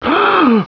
Grunt2
GRUNT2.WAV